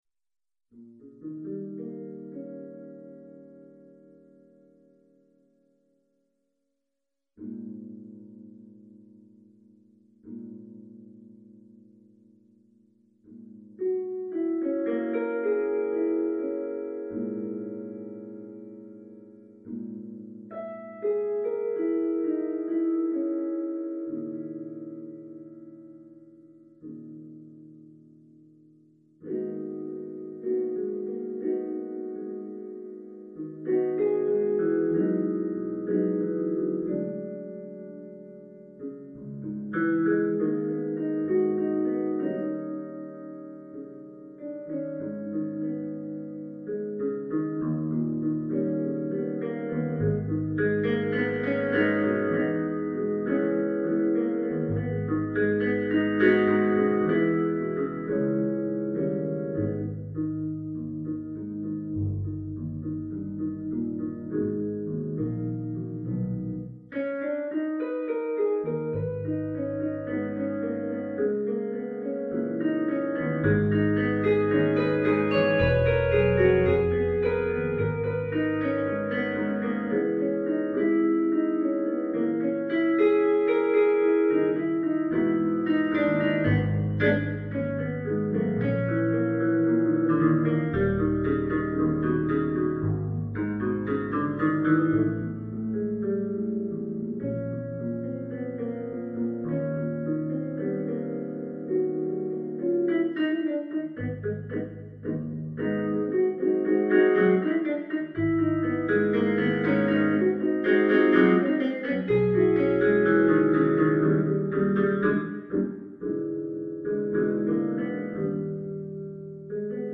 Rain, sent from Berlin